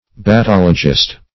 Battologist \Bat*tol"o*gist\, n. One who battologizes.